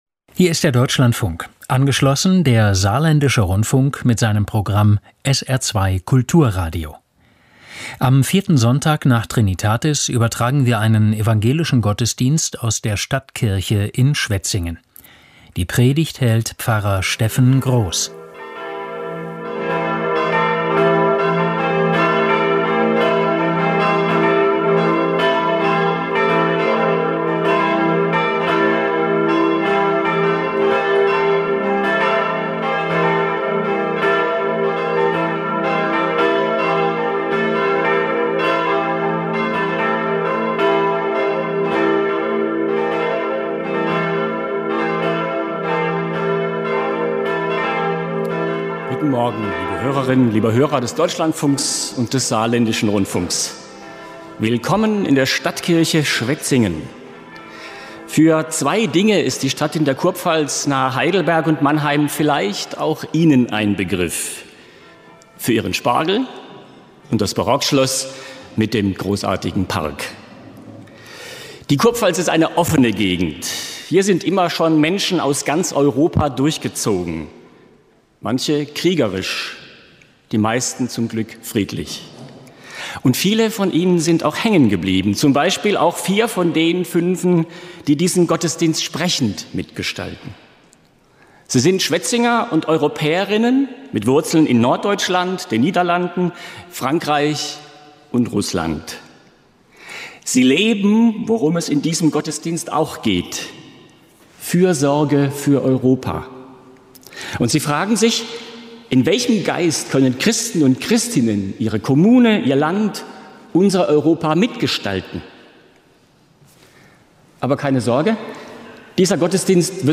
Evangelischer Gottesdienst aus Schwetzingen